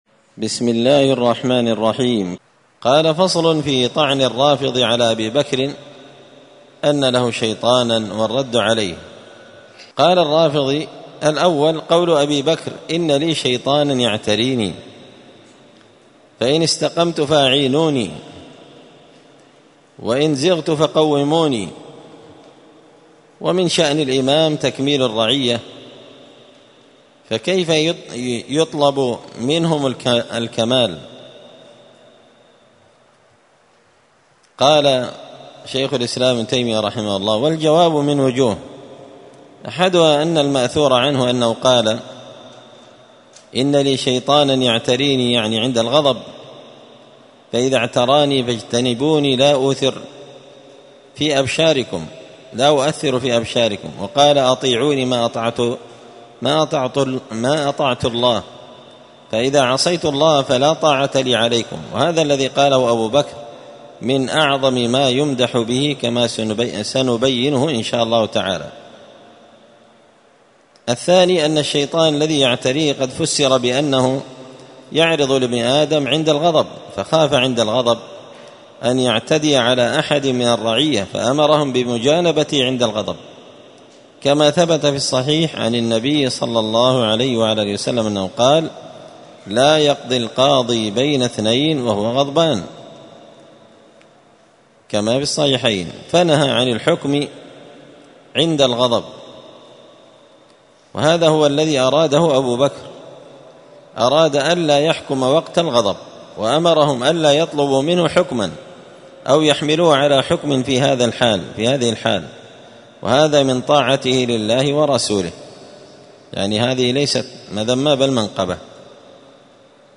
*الدرس السادس والعشرون بعد المائتين (226) فصل في طعن الرافضي على أبي بكر أن له شيطانا والرد عليه*